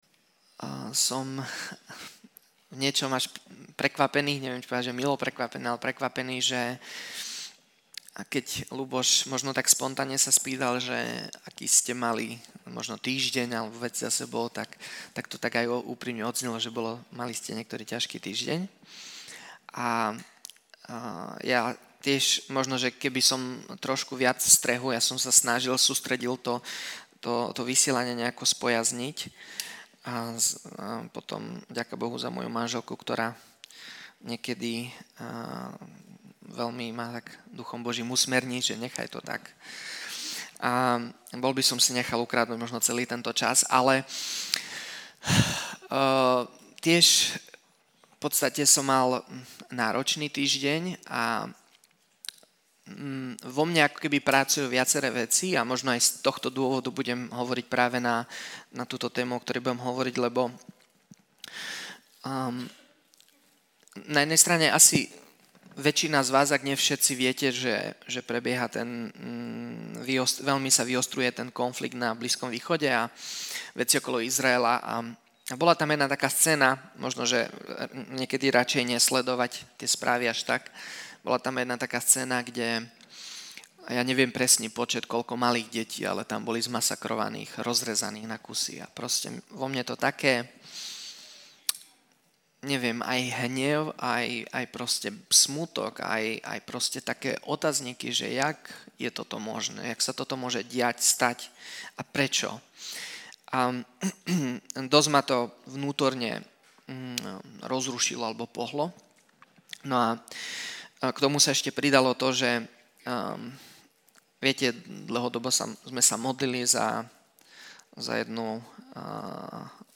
Vypočujte si kázne z našich Bohoslužieb